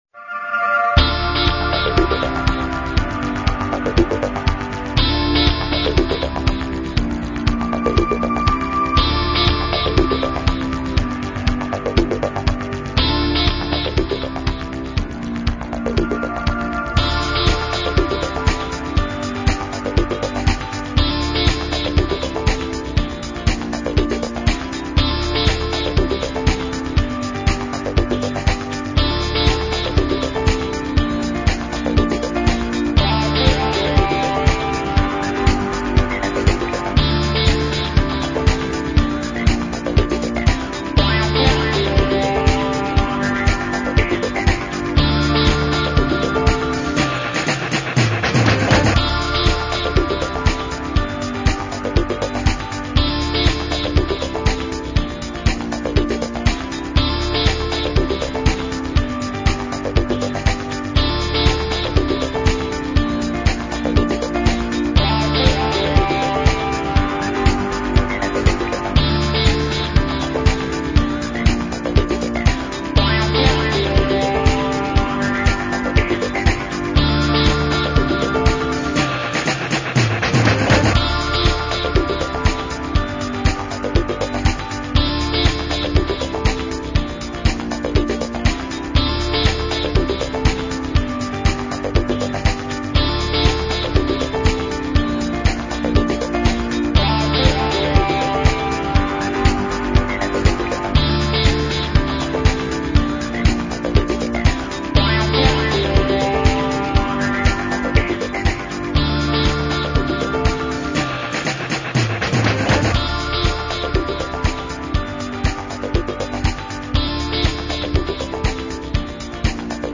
discute de ce sujet avec l’honorable Eric Bukula, député provincial élu de Kinshasa.
Amos Mbokoso, ministre provincial du transport a aussi participé à cet entretien.